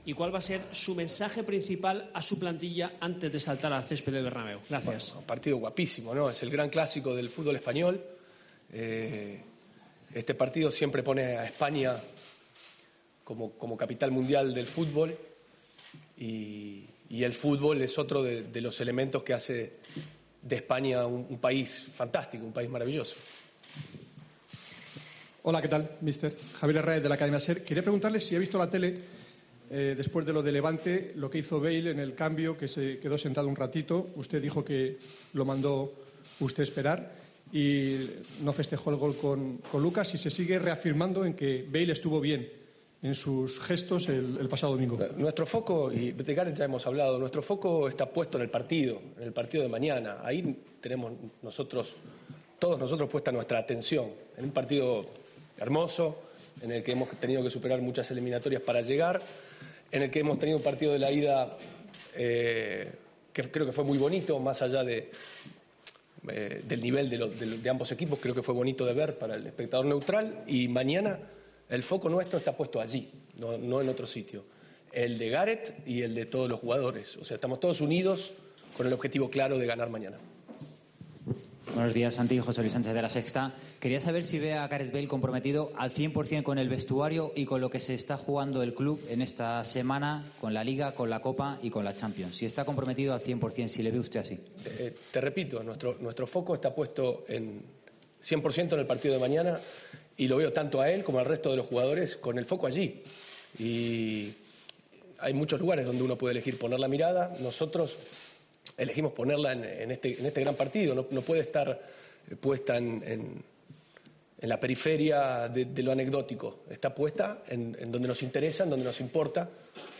Fue la respuesta tipo para cualquier pregunta que nombrase a Bale, hasta seis de las 16 de las que constó la rueda de prensa de Solari.